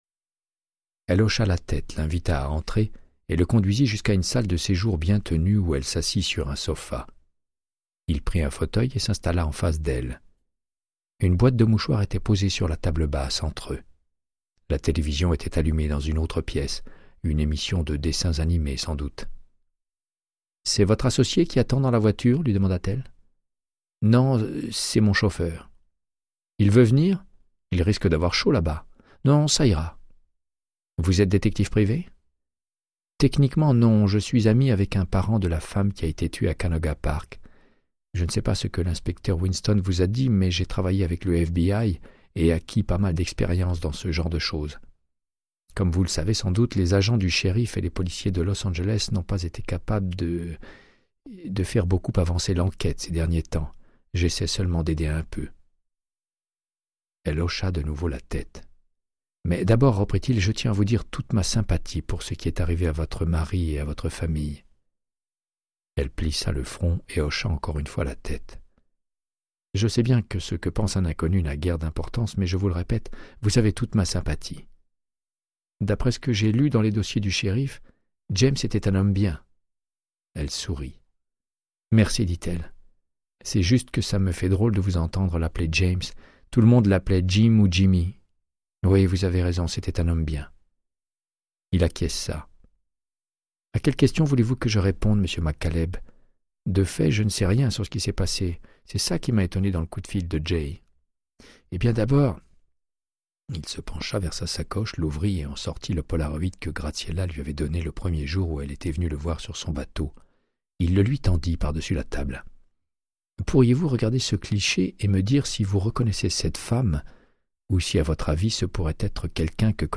Audiobook = Créance de sang, de Michael Connellly - 82